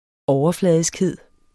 Udtale [ ˈɒwʌˌflæˀðisgˌheðˀ ]